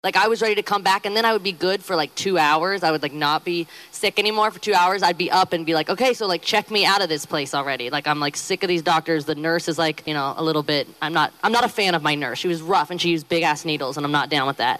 Before the show she held an impromptu press conference to let the world know she is back and in good health – with a new dislike for Doctors!